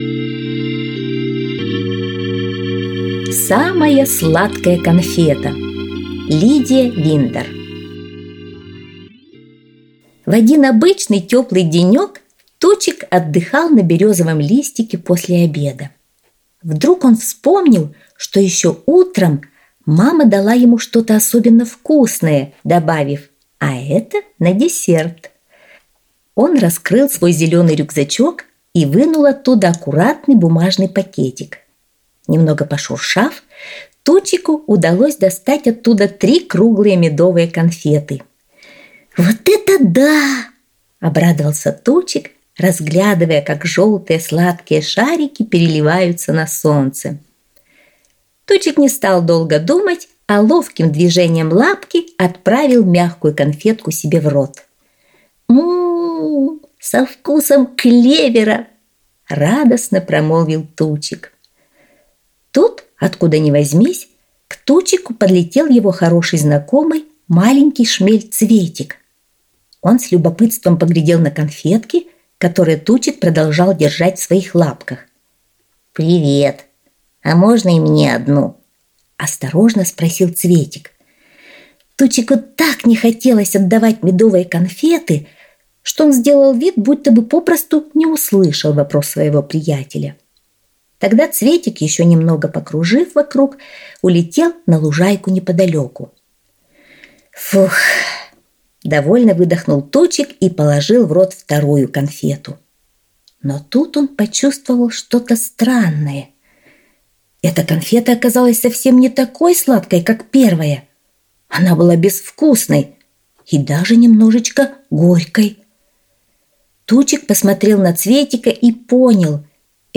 Аудиосказка «Самая сладкая конфета»